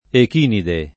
echinide [ ek & nide ]